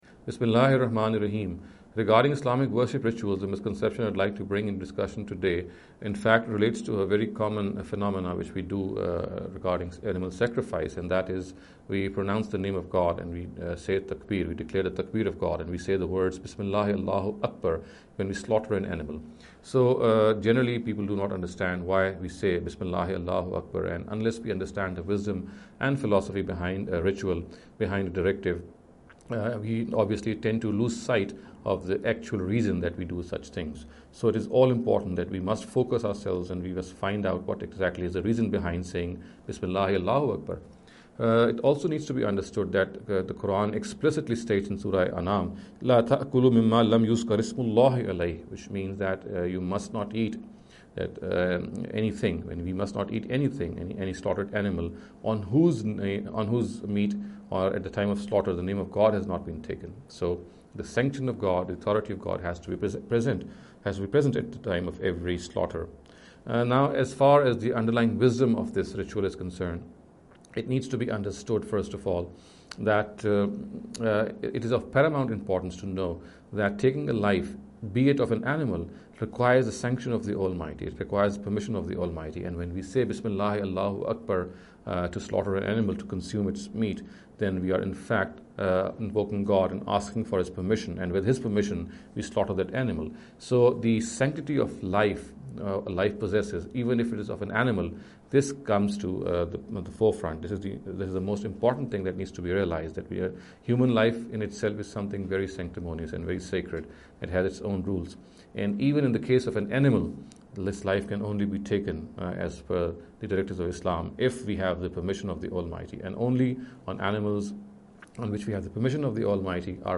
This lecture series will deal with some misconception regarding the Islamic Worship Ritual. In every lecture he will be dealing with a question in a short and very concise manner. This sitting is an attempt to deal with the question 'Saying Takbir when Slaughtering Animals’.